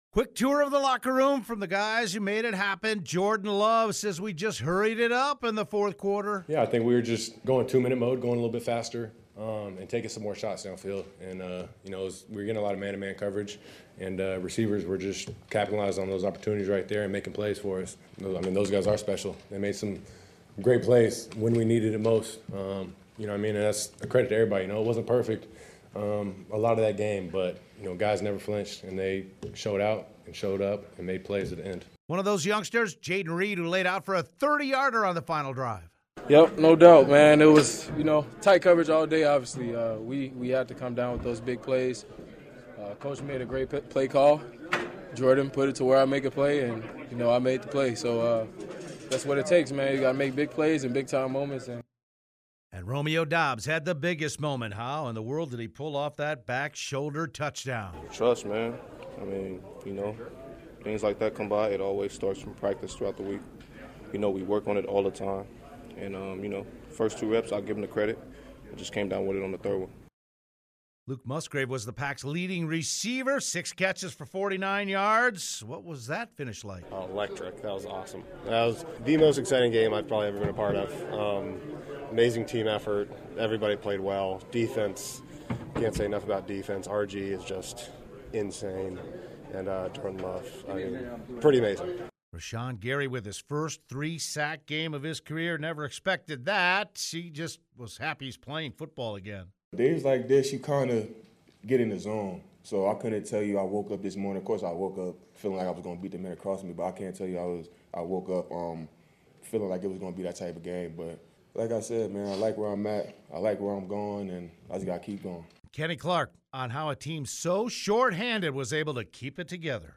There were a host of players who came up big when it looked bleak and I caught up with a few after the game:
saints-locker-room-quotes.mp3